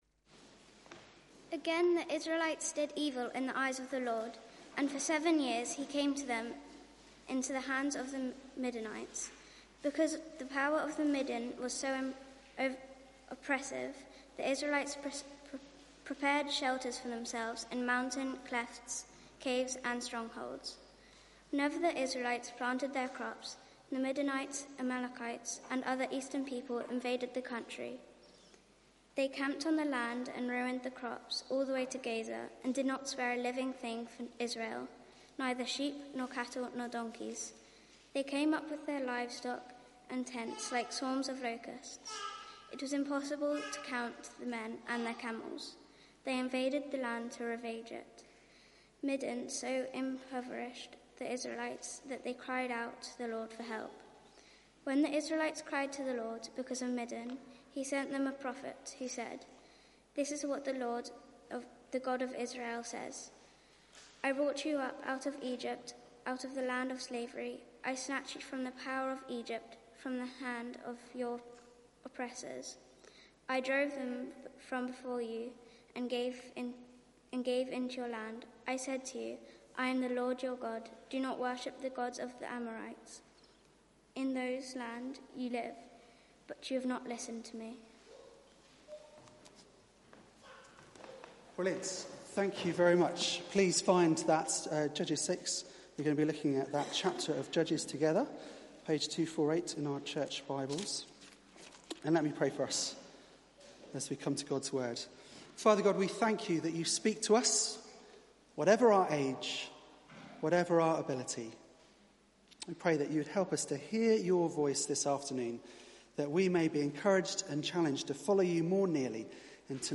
Media for 4pm Service on Sun 27th Oct 2019 16:00
Gideon: the Weak Mighty Warrior Sermon